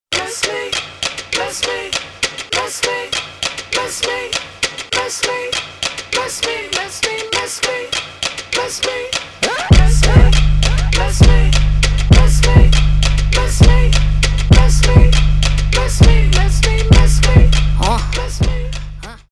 рэп , ритмичные